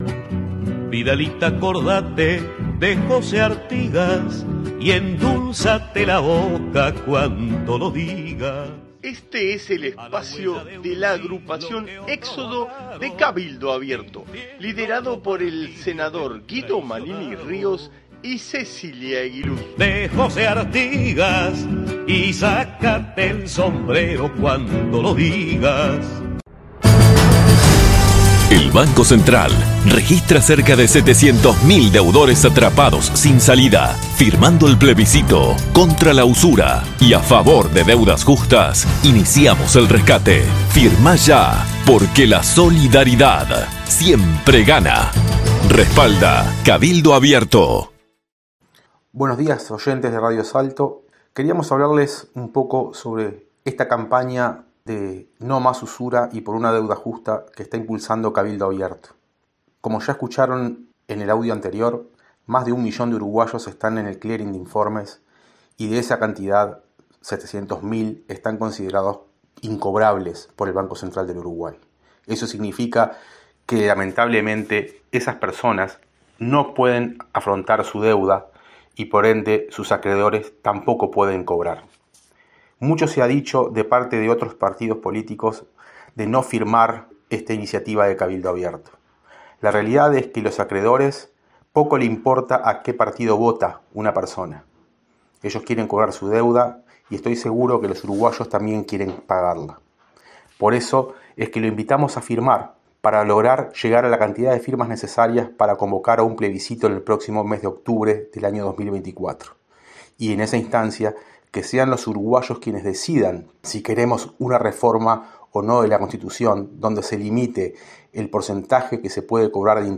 Audición radial de nuestra agrupación para Radio Salto(1120AM) del día 28 de octubre de 2023. Explicación de por qué desde Cabildo Abierto impulsamos esta campaña de recolección de firmas.